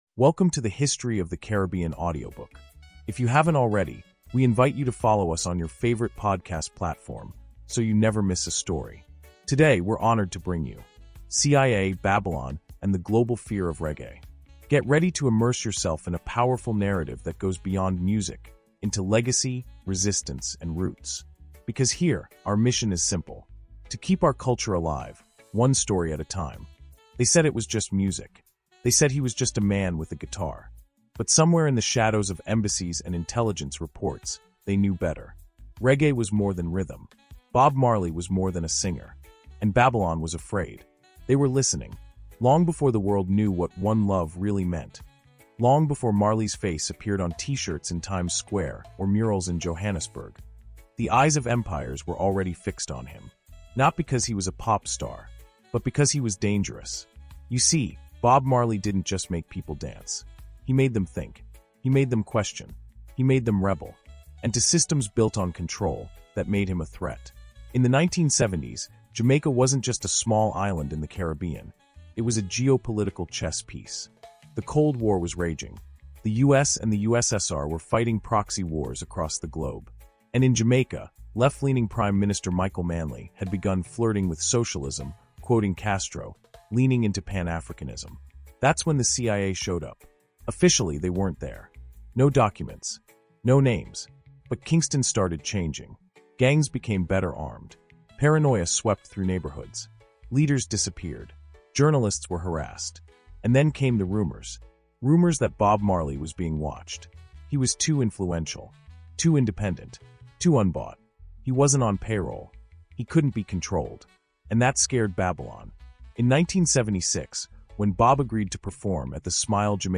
CIA, Babylon, and the Global Fear of Reggae” is a riveting audiobook insight that uncovers the chilling intersection of music, politics, and power.